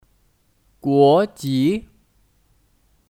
国籍 (Guójí 国籍)